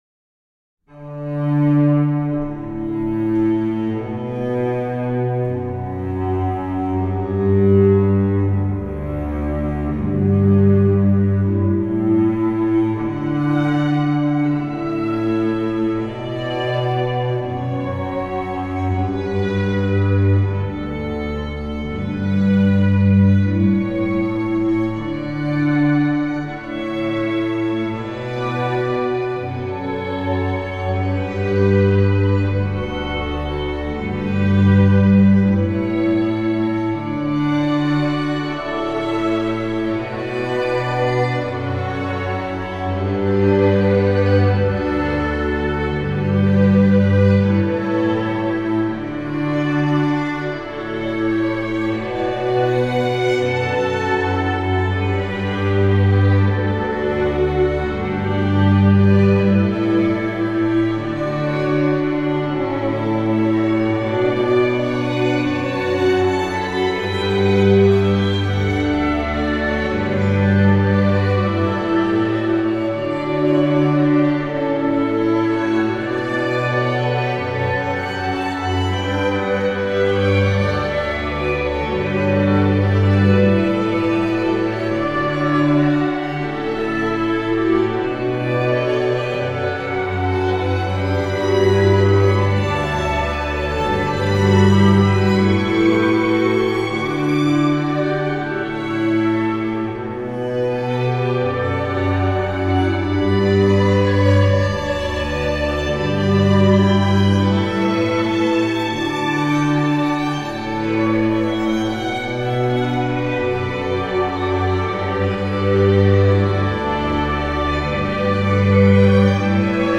Hall Effect Samples
Freeverb3_VST ProG Reverb
Preset - Default Preset
Hall_ProG_Default_Reverb.mp3